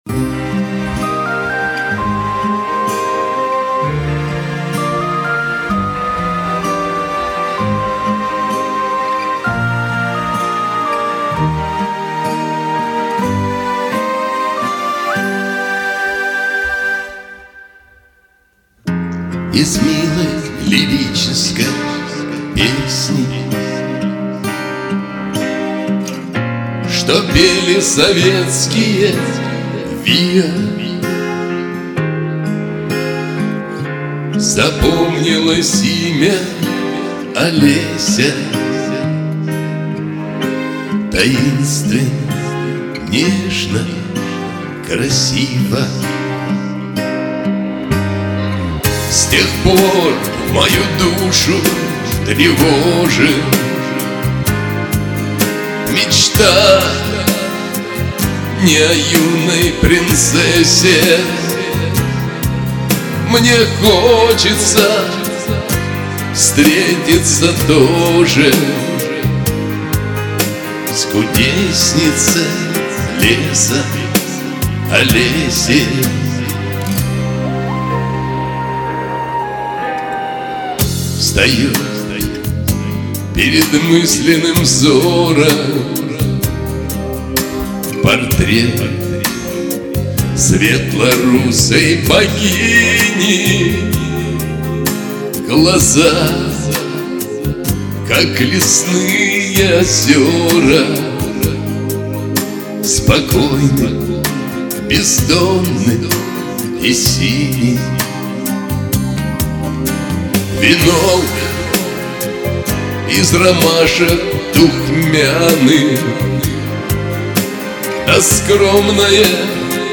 • Песня: Лирика